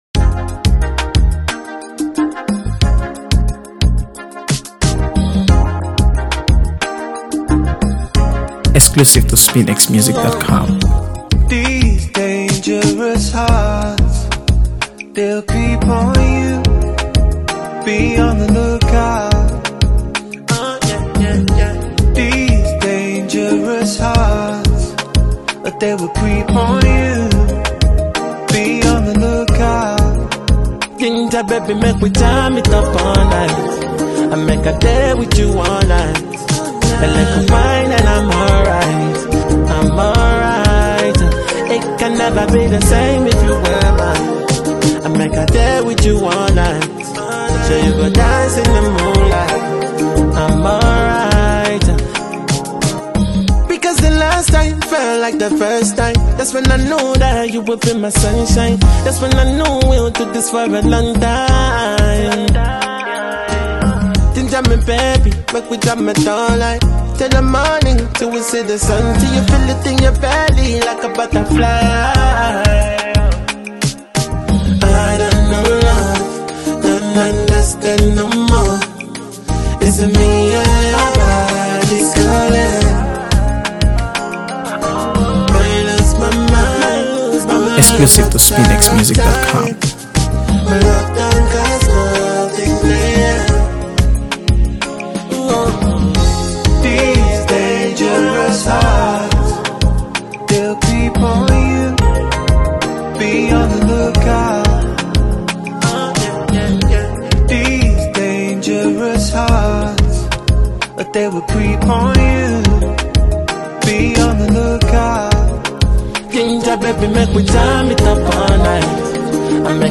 AfroBeats | AfroBeats songs
The sound design is clean yet immersive